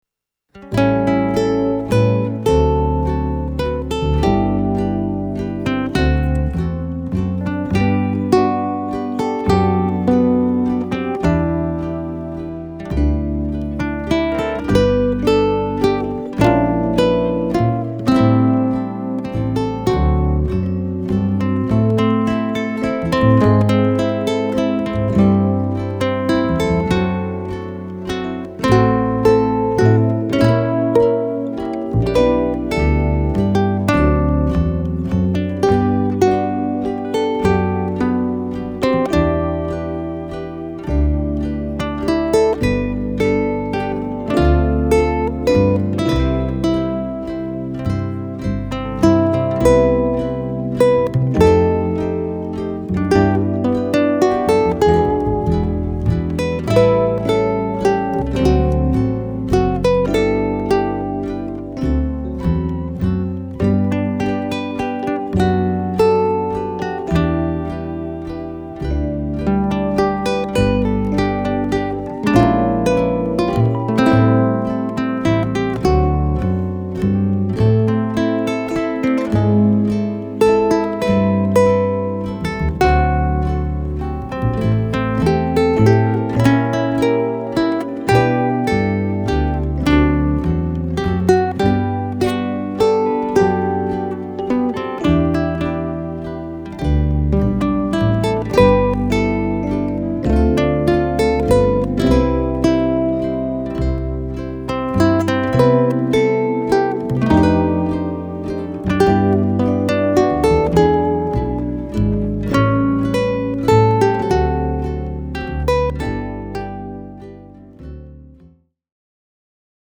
Pua-Lilia-Ukulele.mp3